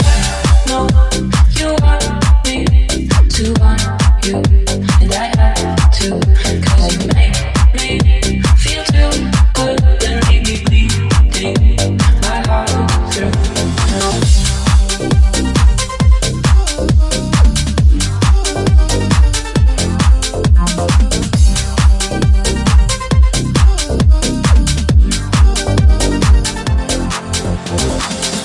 Genere: deep,dance,news